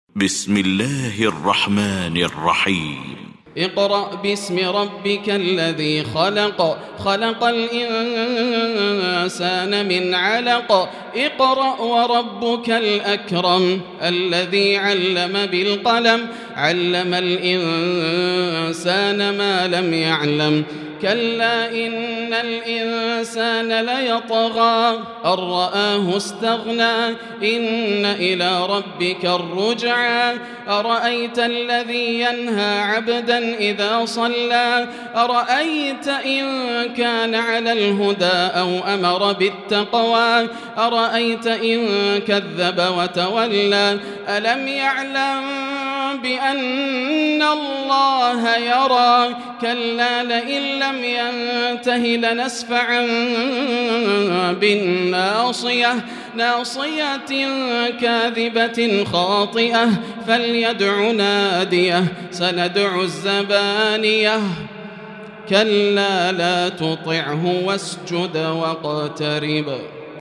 المكان: المسجد الحرام الشيخ: فضيلة الشيخ ياسر الدوسري فضيلة الشيخ ياسر الدوسري العلق